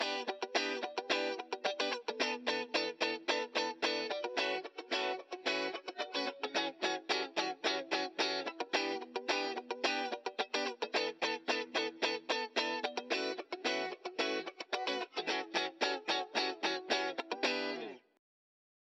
Audio 1.9 - Guitar